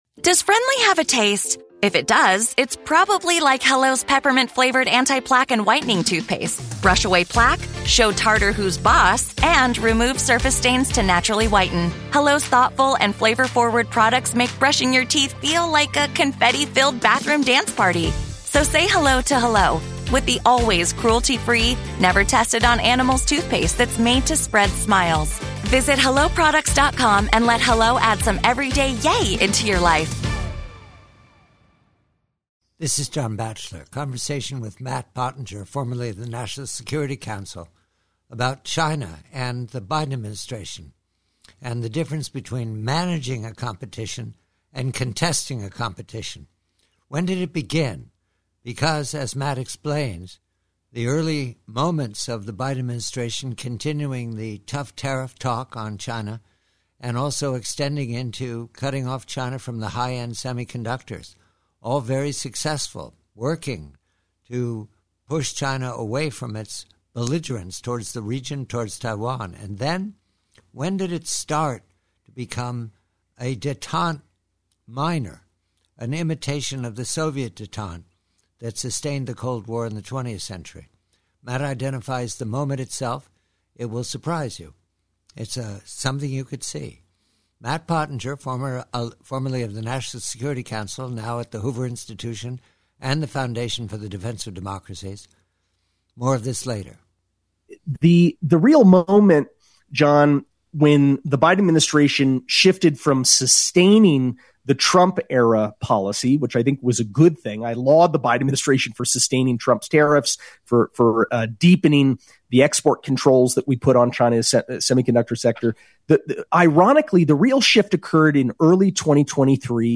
PREVIEW: PRC: Conversation with former Deputy National Security Adviser Matt Pottinger re the moment the Biden Administration flipped from confronting PRC hostiity to managing and accommodating the PRC as in détente of the 20th Century Cold War.